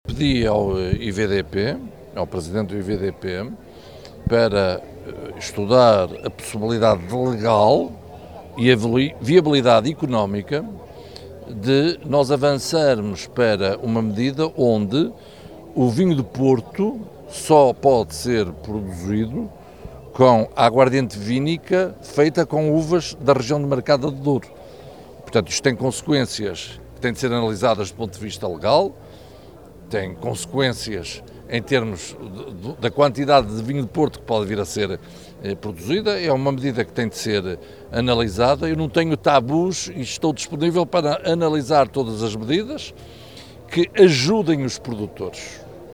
José Manuel Fernandes avançou esta informação, ontem, ao fim da tarde, na abertura da Feira da Maçã, Vinho e Azeite de Carrazeda de Ansiães: